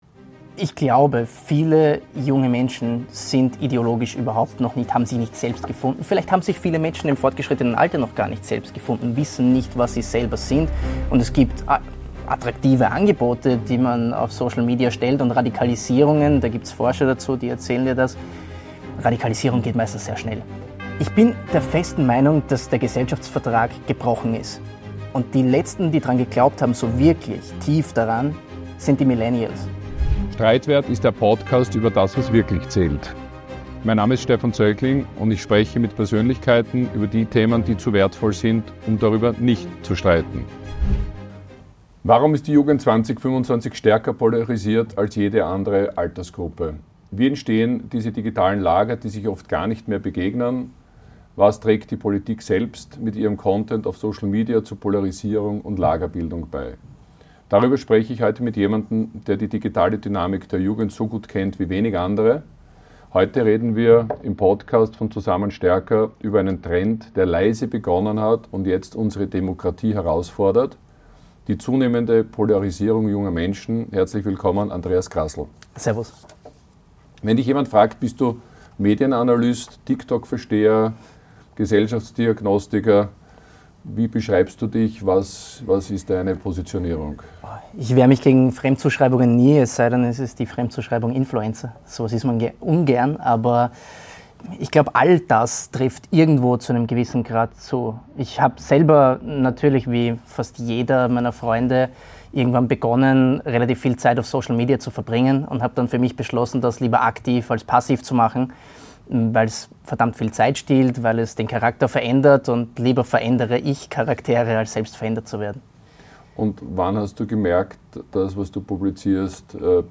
Ein Gespräch über einen Generationenbruch, Vertrauensverlust in Institutionen und darüber, warum radikale Vernunft genauso gut Reichweite erzeugen kann wie extreme Positionen.